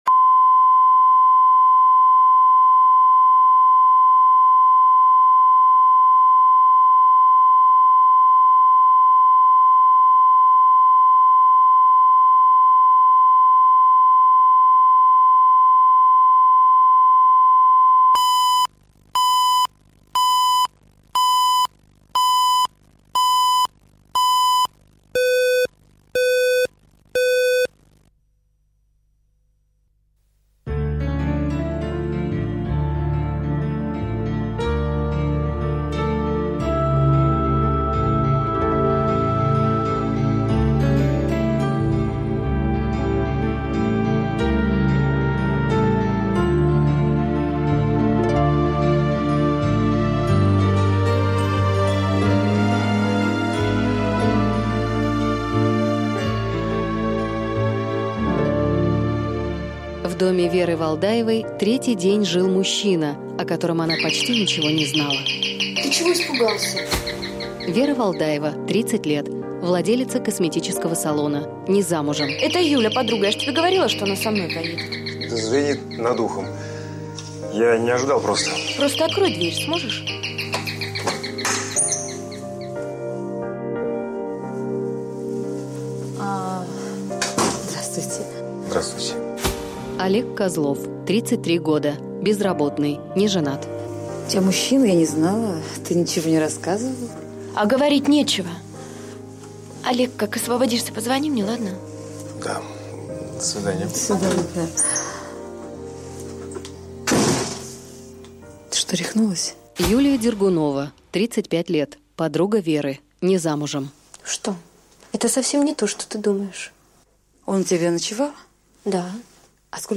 Аудиокнига Под колесами любви | Библиотека аудиокниг